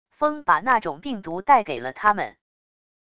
风把那种病毒带给了他们wav音频生成系统WAV Audio Player